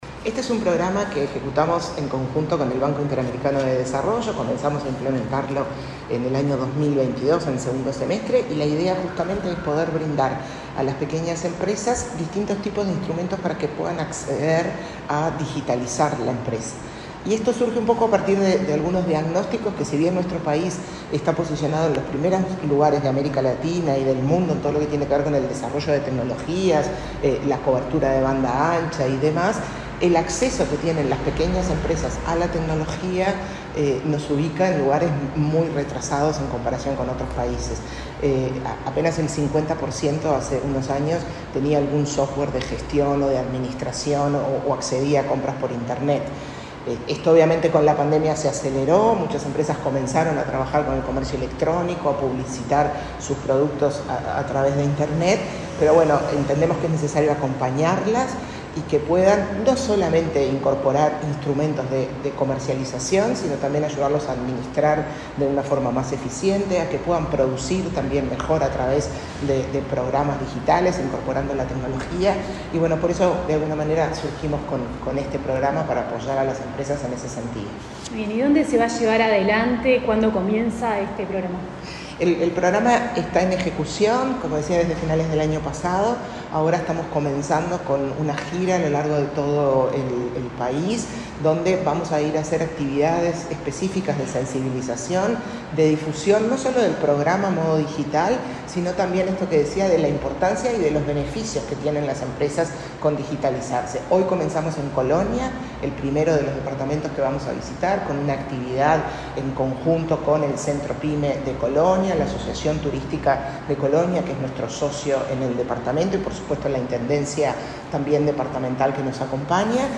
Entrevista a la presidenta de ANDE, Carmen Sánchez